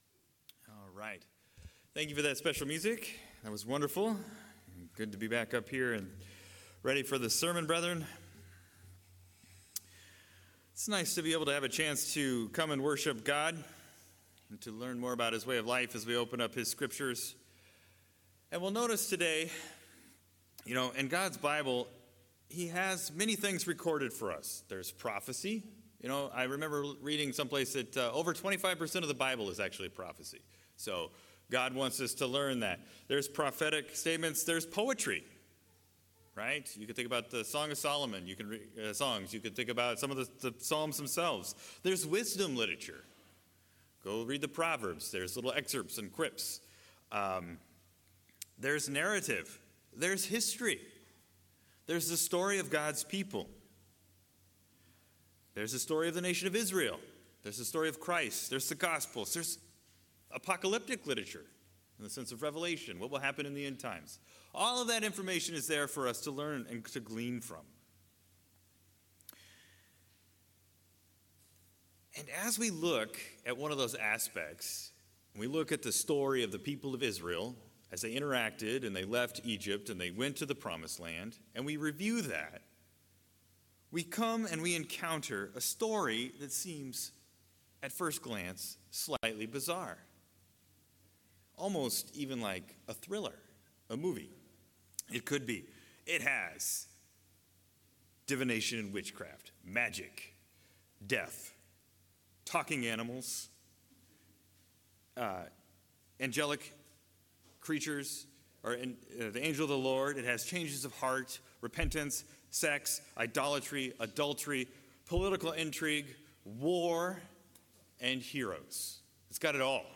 In todays sermon we are going to look at the prophet Balaam and lessons that we can learn as Christian's as we explore his life and interactions with the Israelites